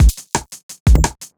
Index of /neuro/Stanza/Drums/Drum Loops